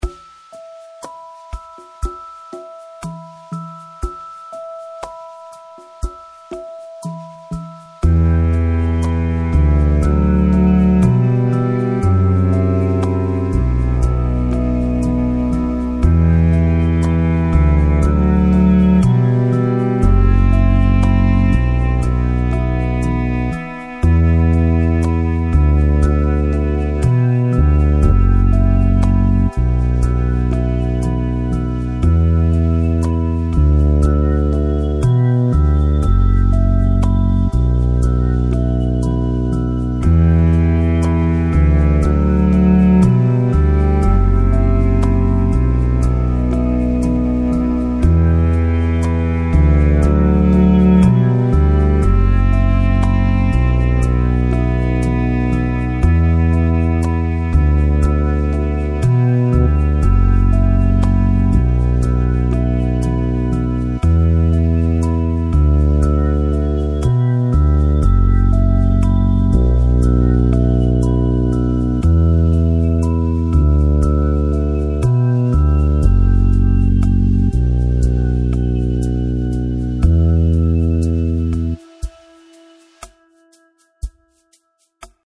Texturas orquestales y ambientales electrónicas